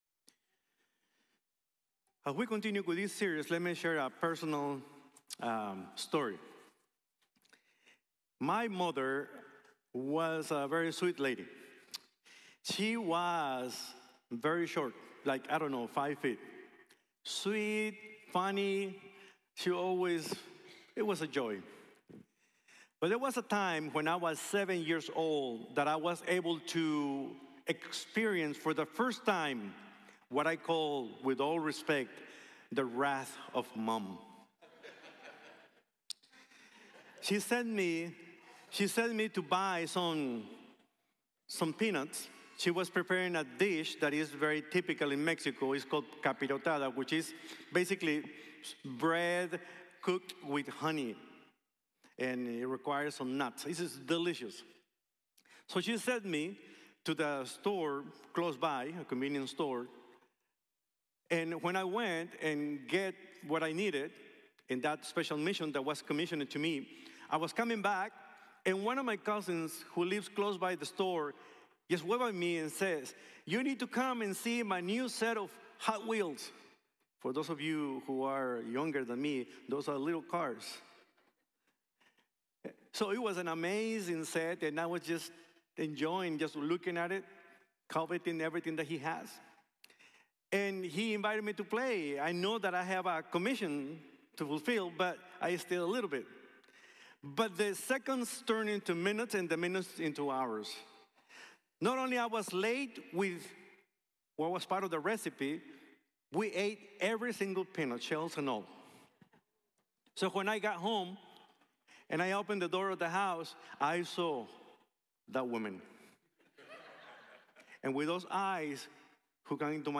From Wrath to Grace | Sermon | Grace Bible Church